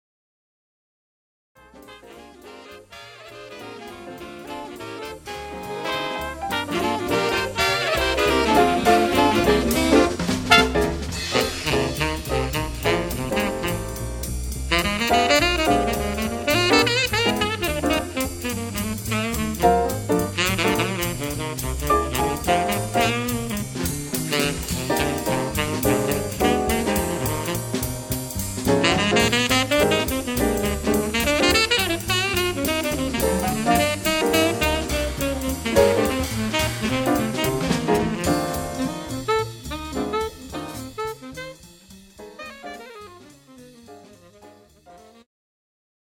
The Best In British Jazz
Recorded at The Sound Cafe, Midlothian